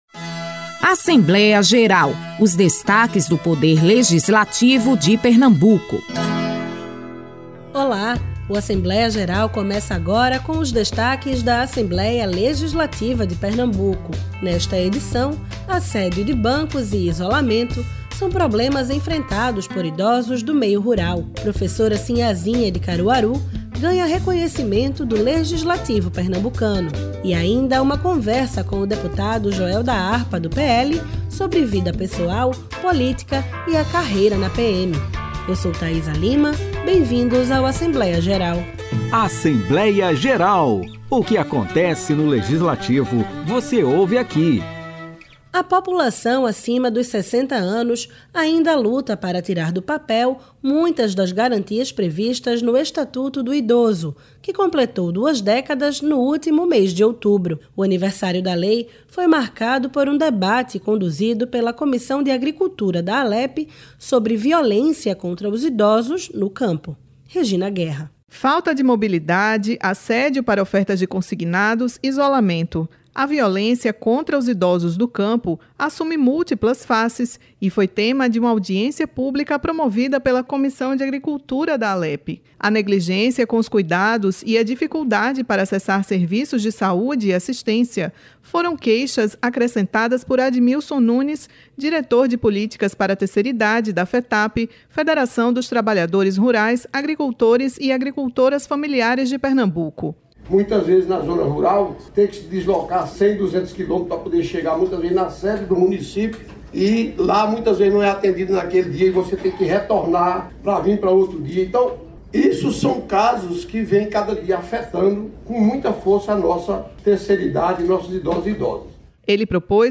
Na edição desta semana do programa Assembleia Geral, é possível conferir os detalhes de uma audiência da Comissão de Agricultura sobre a violência contra os idosos do campo. Também apresentamos uma reportagem sobre o ritmo que é sinônimo de Carnaval em Pernambuco: o frevo. E na entrevista da semana, uma conversa com Joel da Harpa (PL)